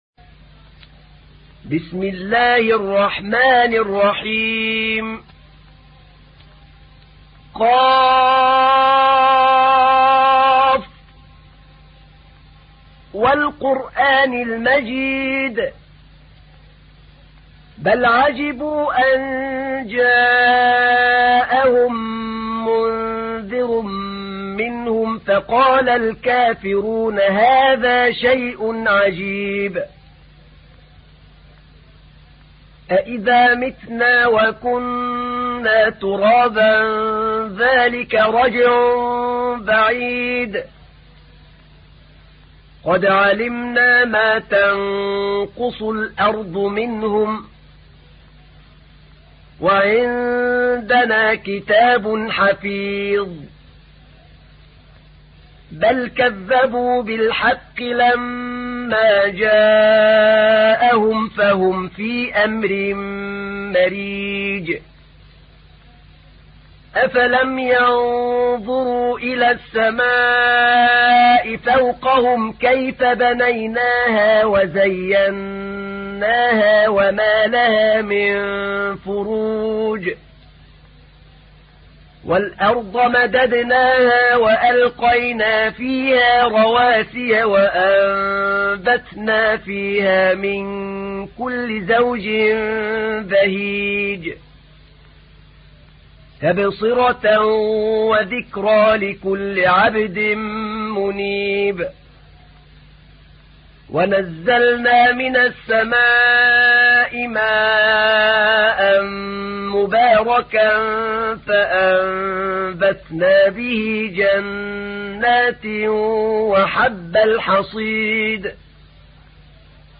تحميل : 50. سورة ق / القارئ أحمد نعينع / القرآن الكريم / موقع يا حسين